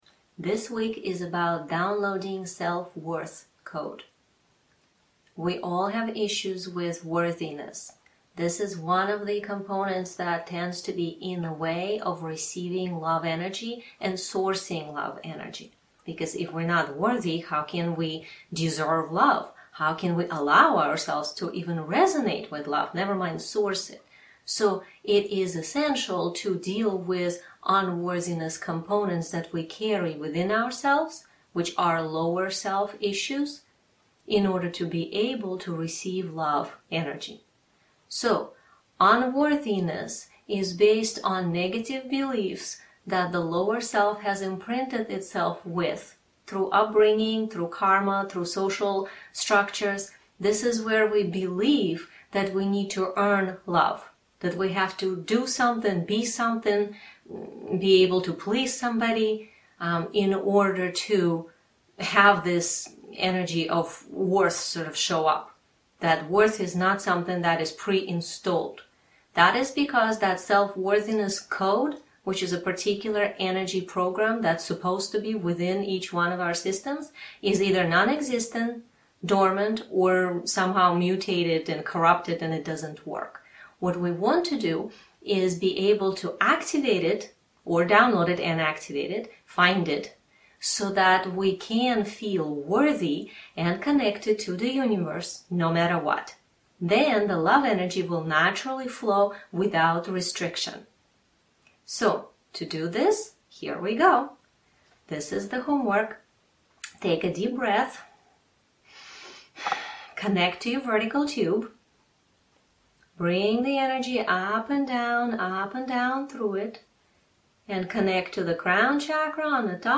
2- DOWNLOAD the AUDIO into your phone/computer so that you can listen to this meditation again and PRACTICE;